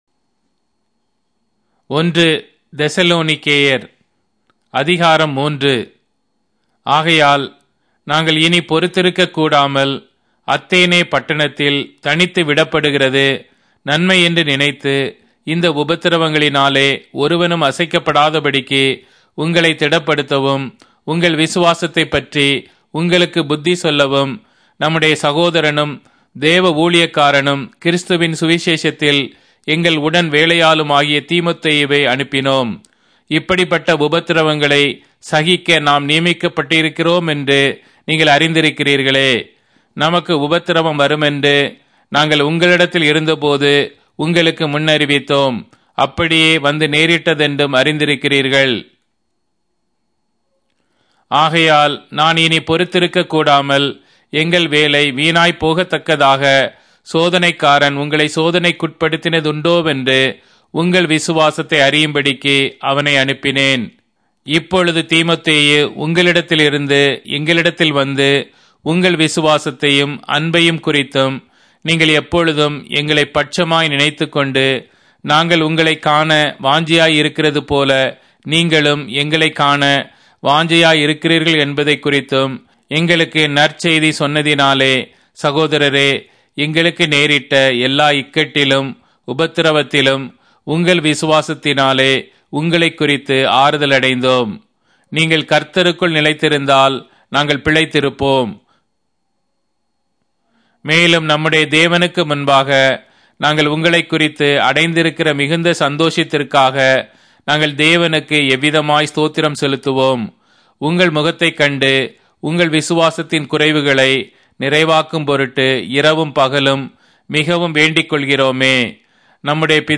Tamil Audio Bible - 1-Thessalonians 4 in Mov bible version